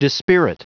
Prononciation du mot dispirit en anglais (fichier audio)
Prononciation du mot : dispirit